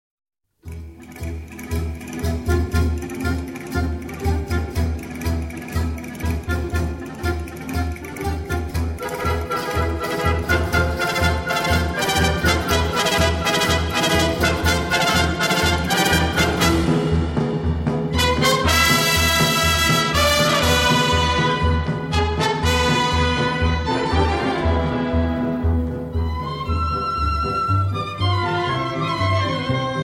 Dance: Paso Doble 60